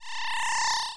shriek.wav